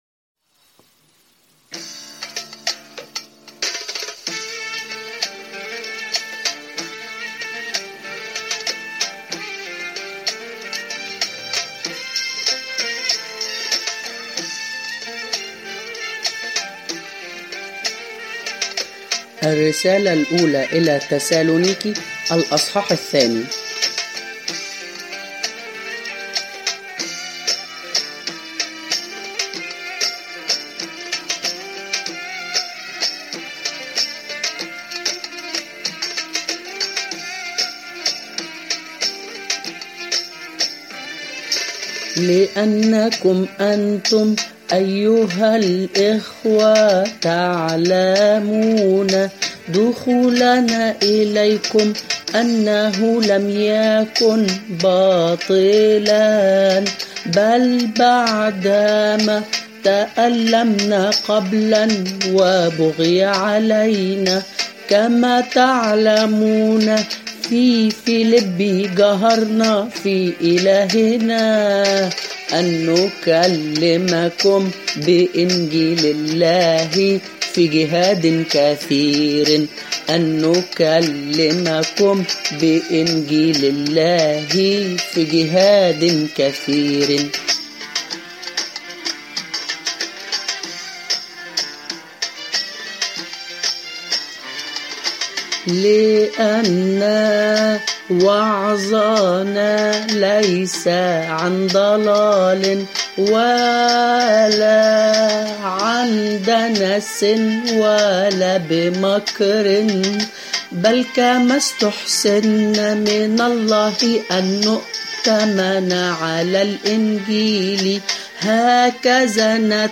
إصحاحات الرسالة الأولى إلى تسالونيكي مترنمة على وزن ألحان كنيسة وترانيم تراثية معروفة